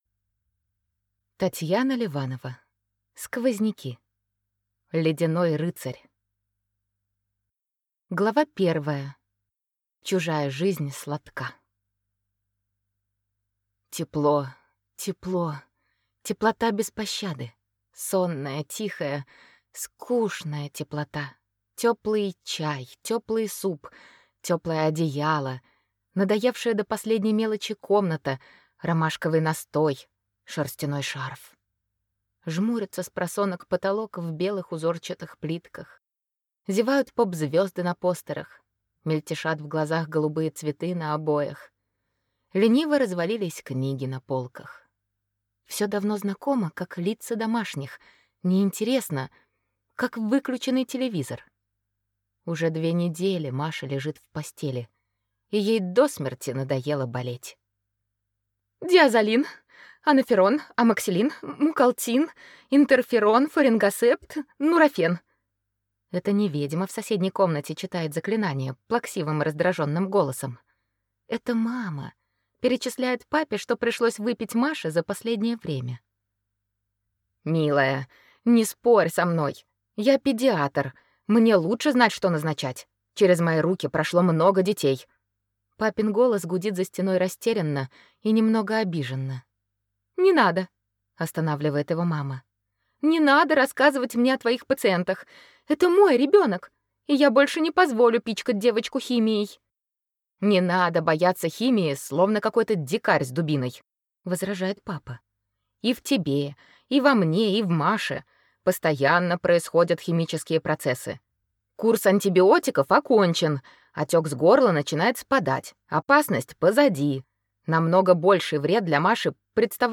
Аудиокнига Ледяной рыцарь | Библиотека аудиокниг